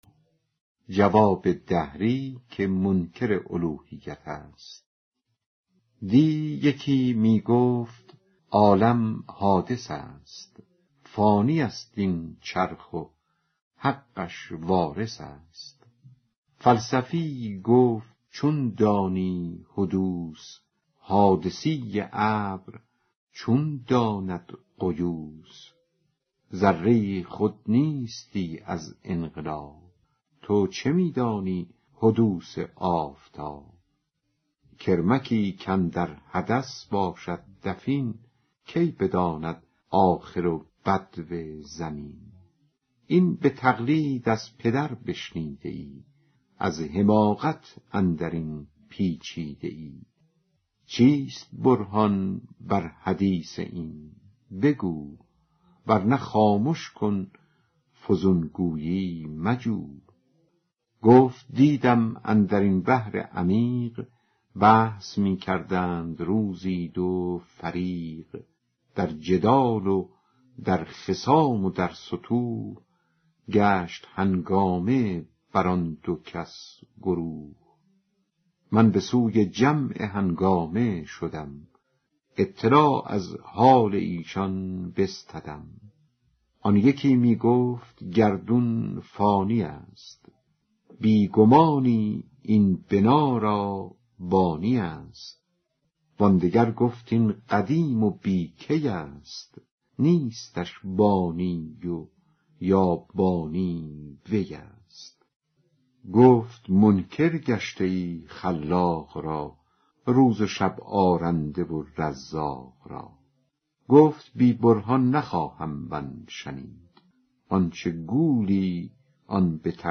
دکلمه جواب دهری که منکر الوهیت و عالم را قدیم می گوید